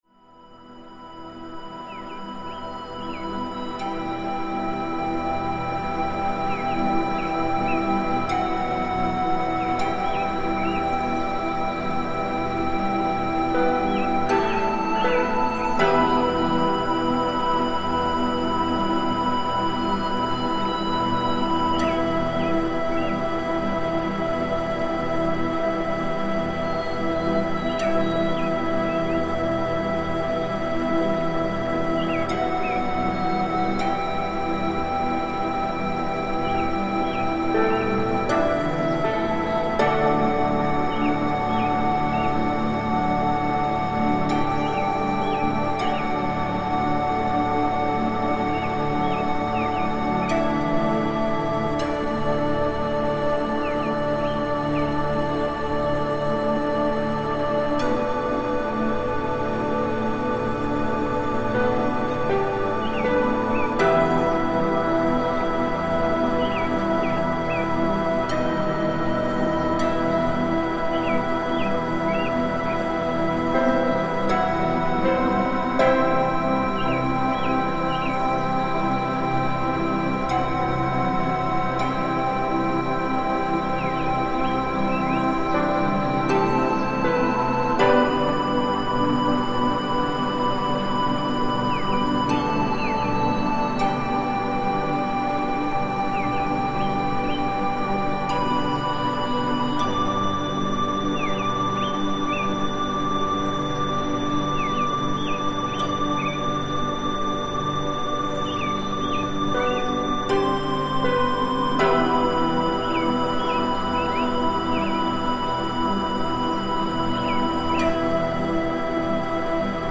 Медитация